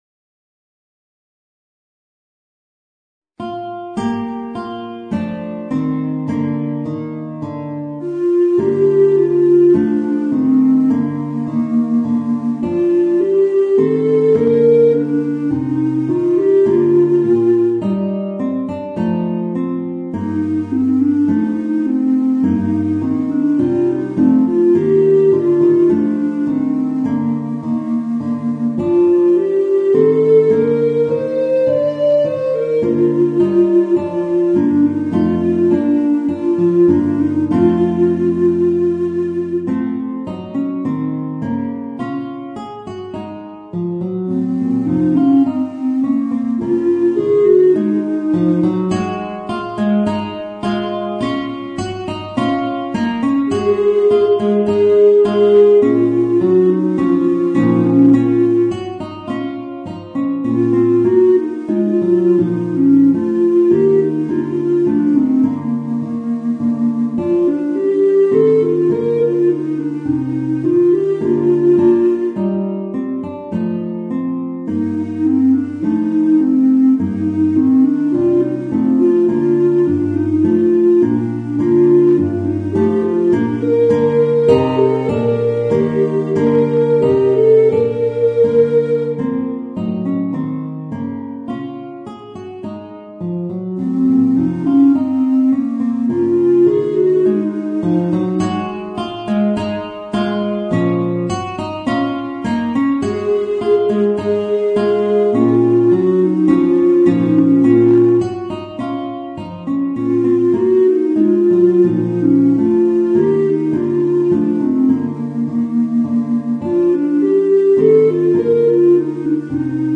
Voicing: Bass Recorder and Guitar